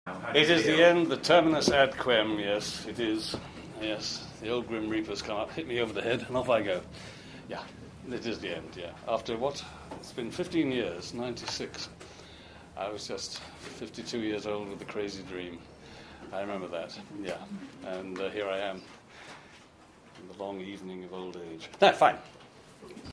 But first here are a few short audio extracts from John’s final Midsomer press conference: